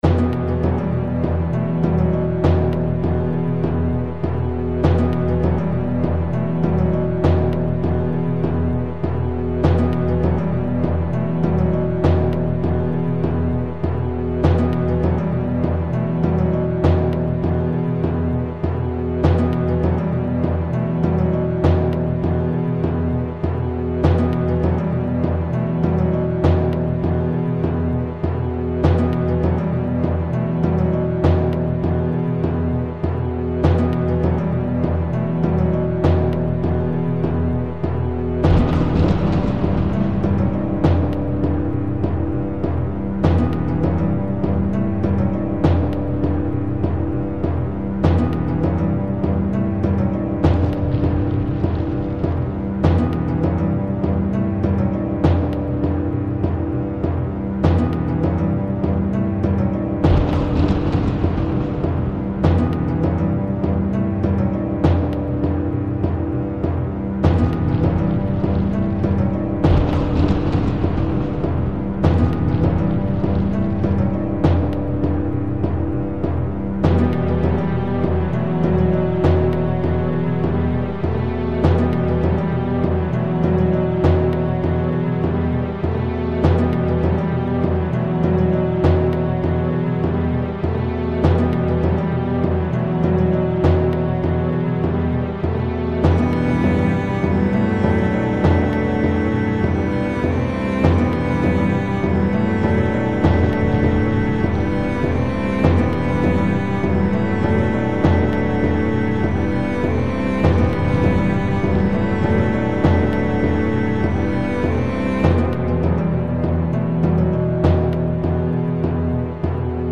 New age Нью эйдж